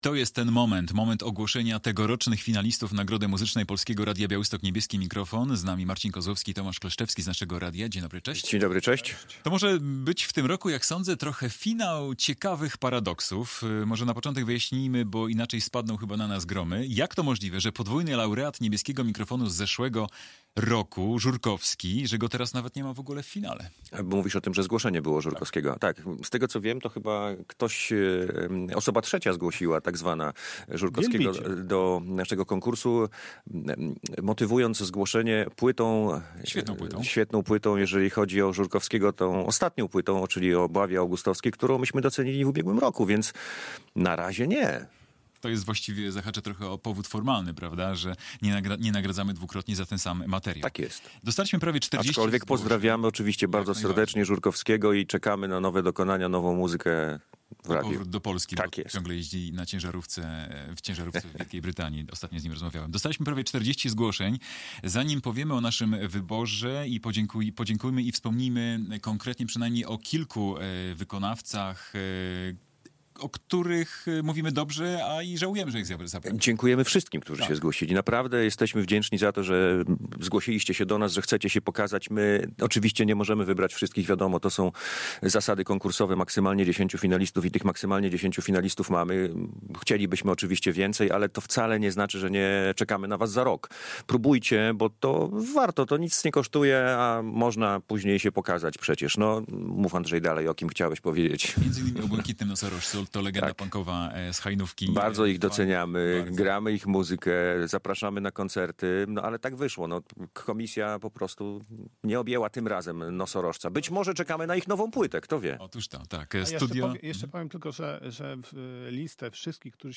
jurorzy konkursu Niebieski Mikrofon Polskiego Radia Białystok